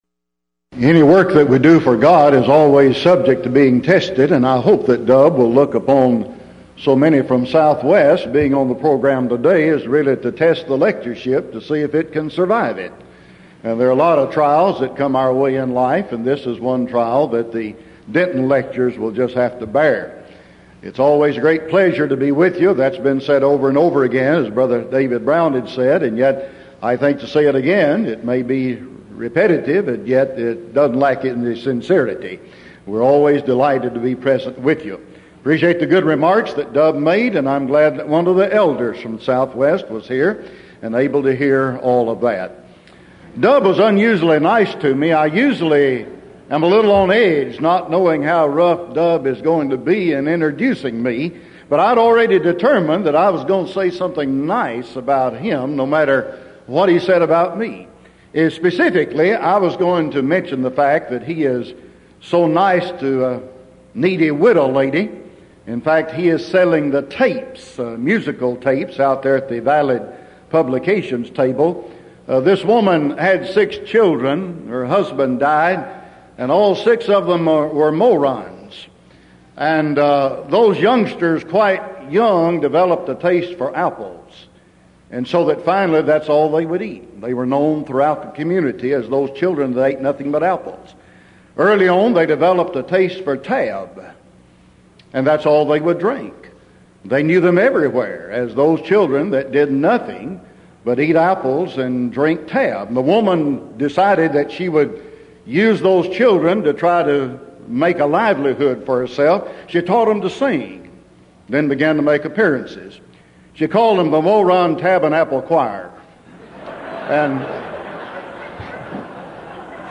Title: DISCUSSION FORUM: The Meaning Of Biblical Faith